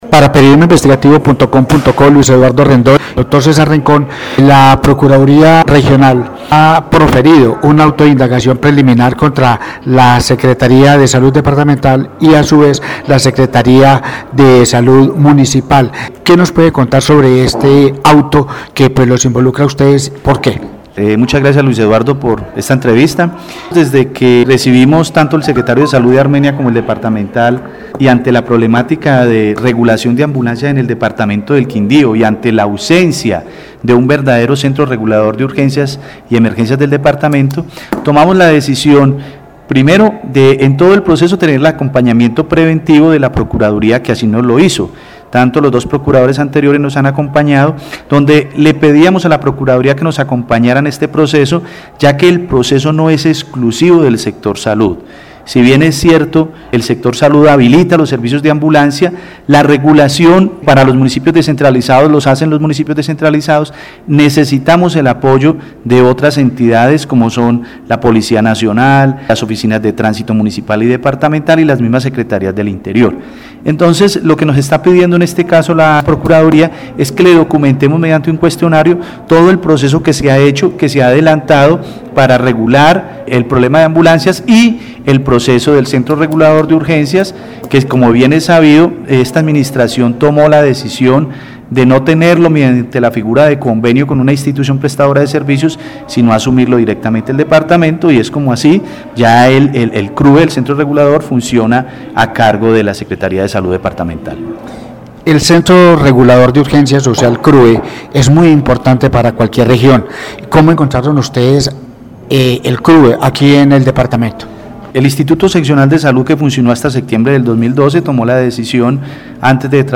Audio Secretario de Salud Departamental Dr. Cesar Rincón